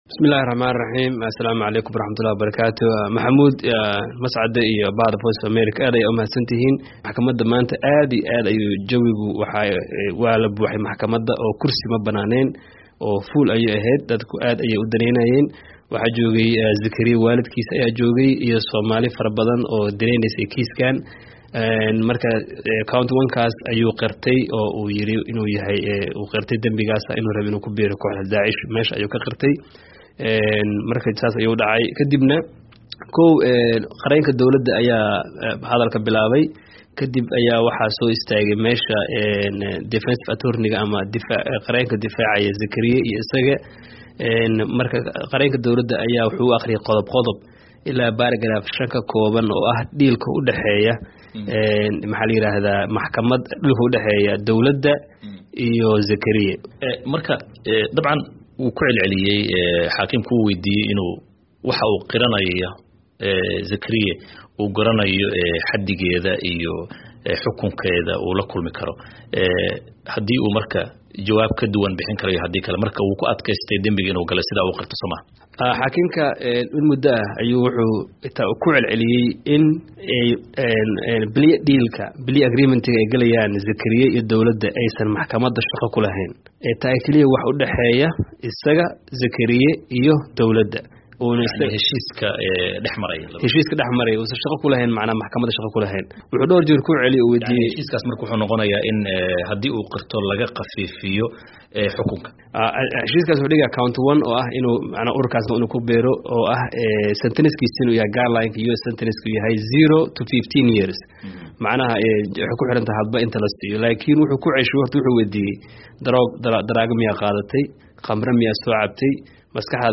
Dhageyso wareysiga Qirashada